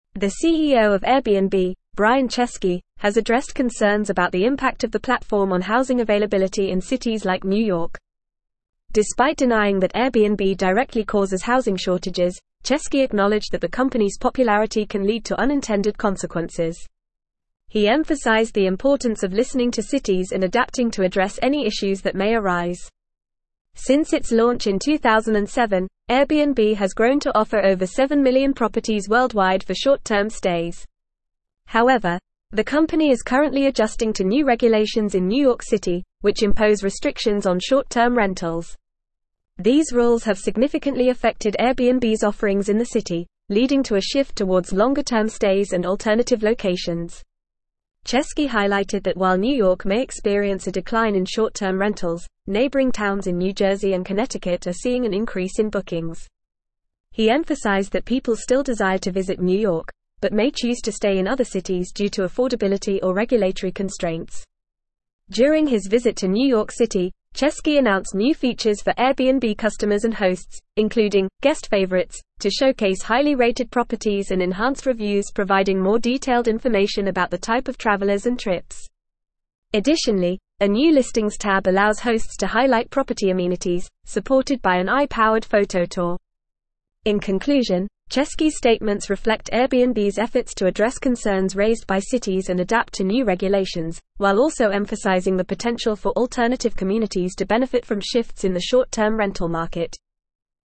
English-Newsroom-Advanced-FAST-Reading-Airbnb-CEO-Addresses-Housing-Concerns-Adapts-to-Regulations.mp3